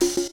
Closed Hats
Wu-RZA-Hat 9.WAV